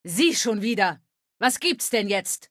Datei:Femaleadult01default ms02 greeting 000ac043.ogg
Fallout 3: Audiodialoge